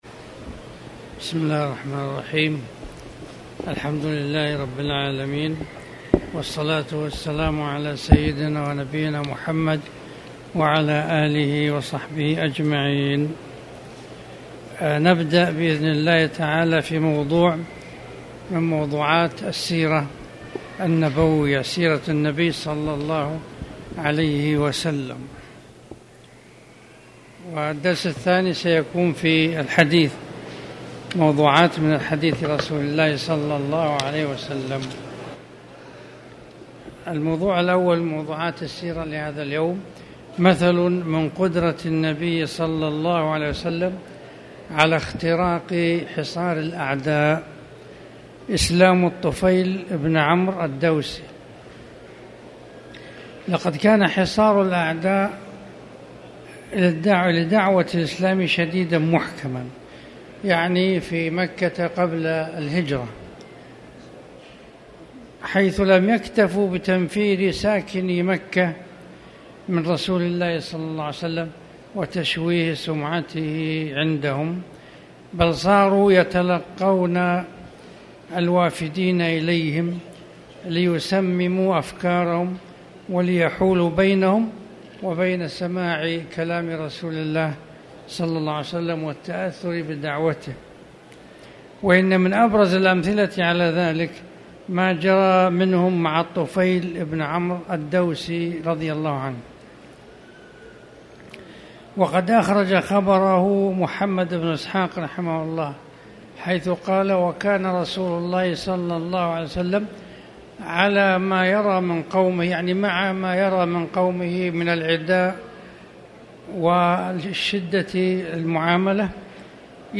تاريخ النشر ٢٩ شوال ١٤٣٨ هـ المكان: المسجد الحرام الشيخ